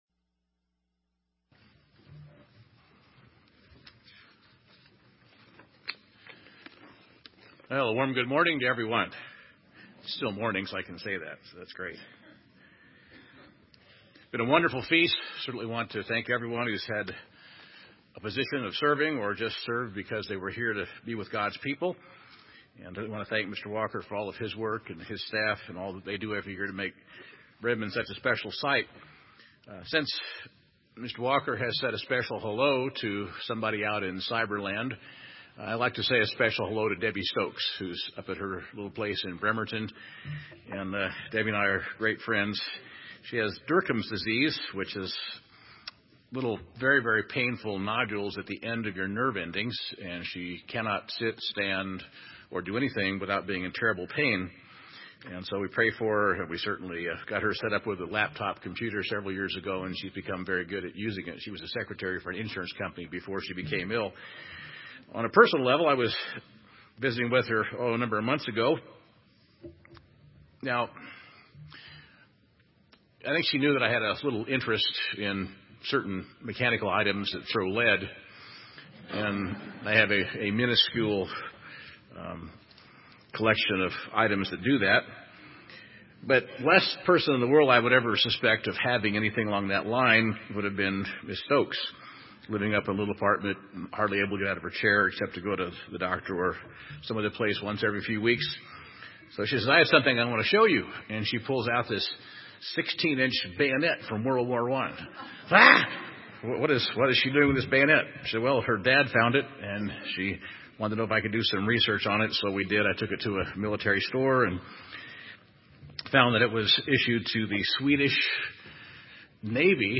This sermon was given at the Bend, Oregon 2015 Feast site.